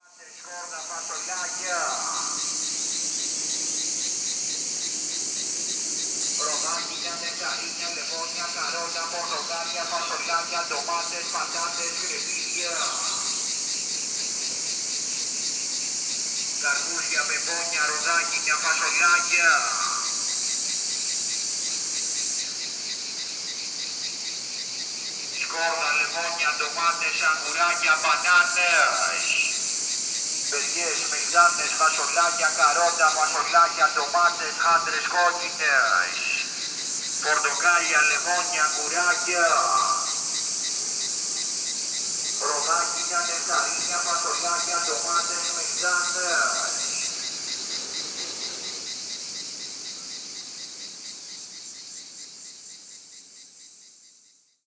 MOBILE GROCER ("O MANAVIS") PELION, GREECE